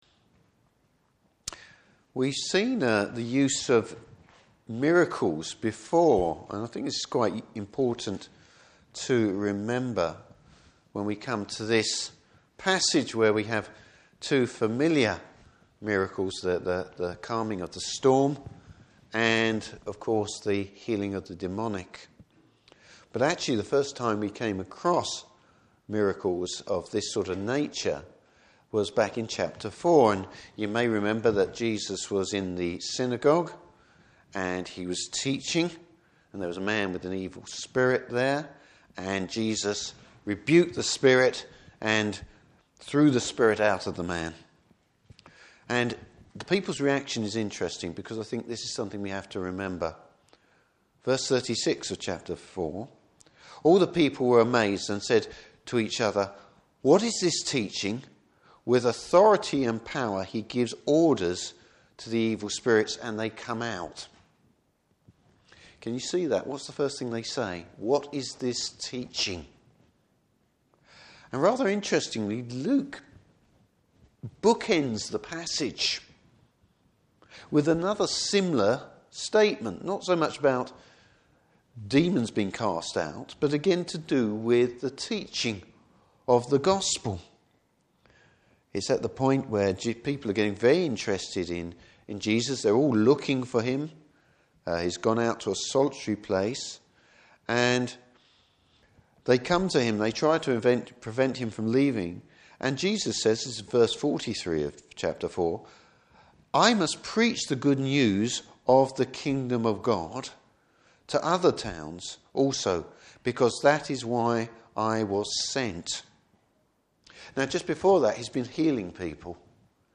Service Type: Morning Service Bible Text: Luke 8:22-39.